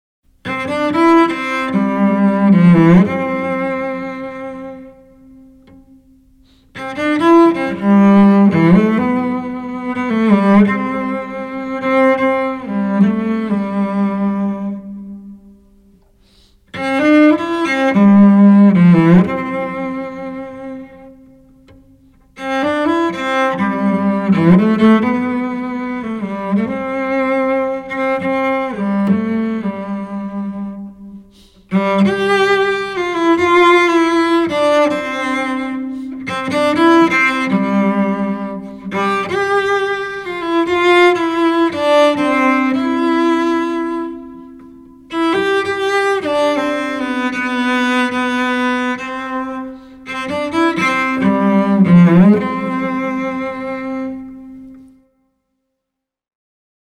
Solo cello Tracks recorded at FTM Studio in Denver, Colorado